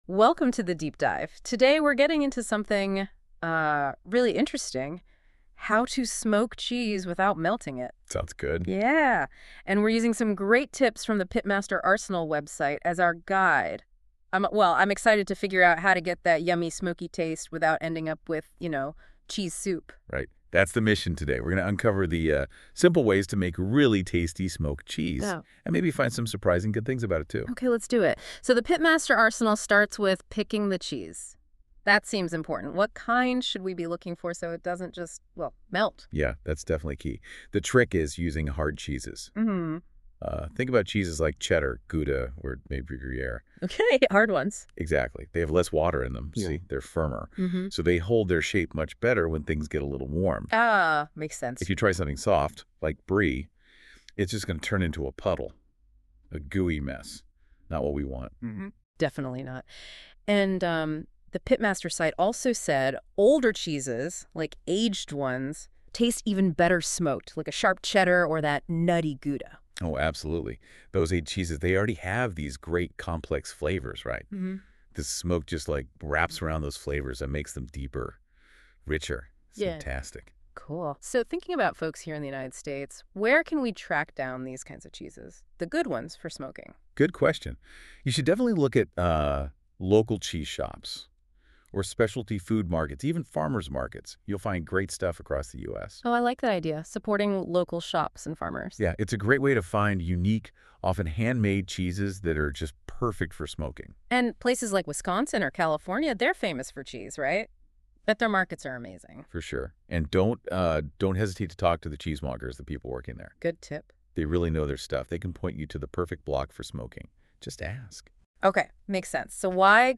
Like a Voiceover Instead?